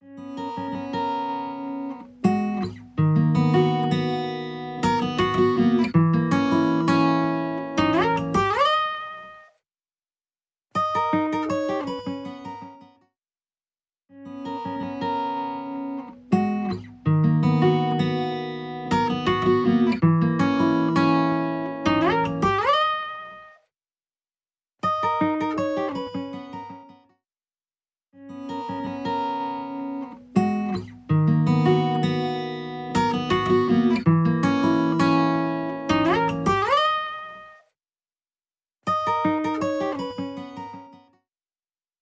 入力信号の高周波情報を強化し、音源に活力を簡単に付与
Sheen-Machine-Repair.wav